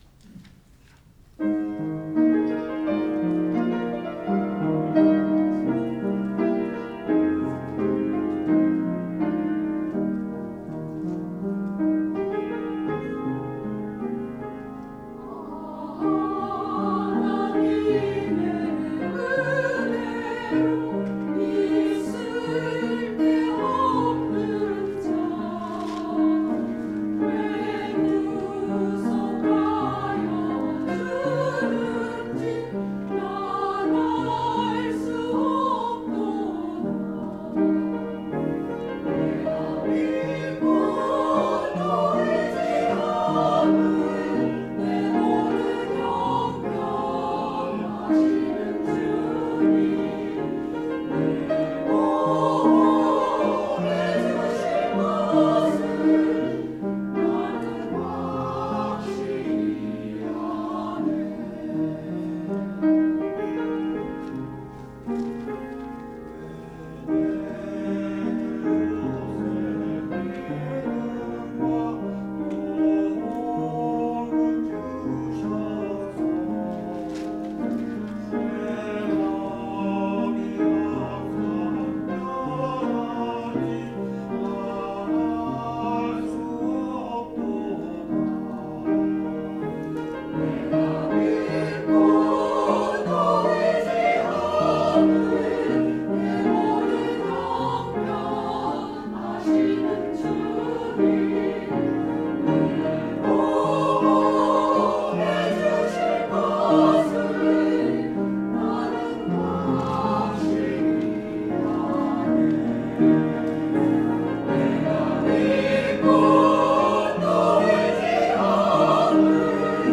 찬양대
[주일 찬양] 아 하나님의 은혜로